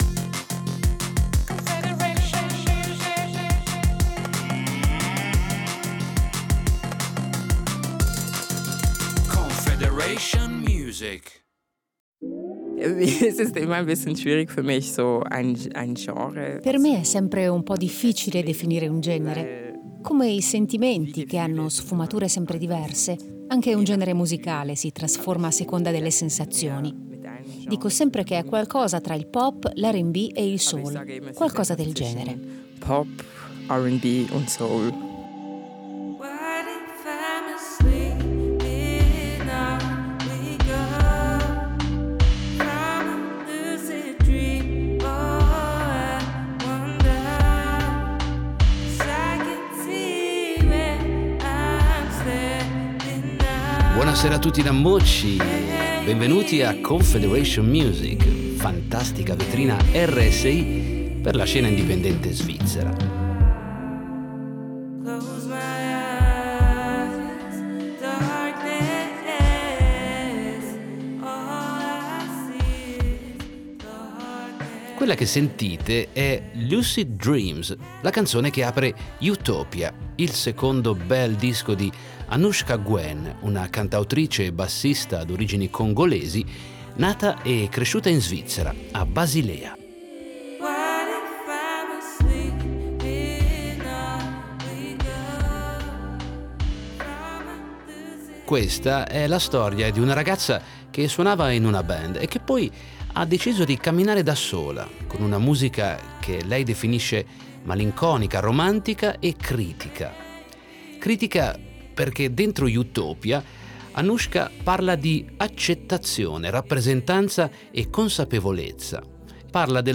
cantautrice e bassista d’origini congolesi
malinconica, romantica e critica